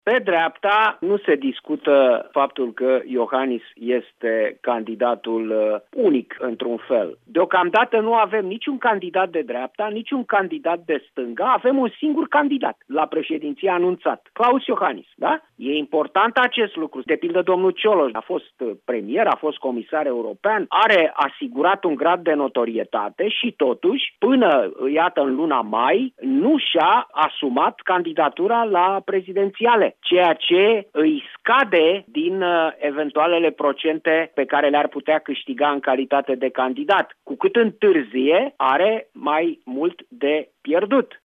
Gazetarul şi scriitorul Cristian Tudor Popescu: